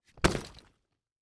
household
Duffle Bag Drop Cement Floor